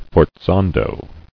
[for·zan·do]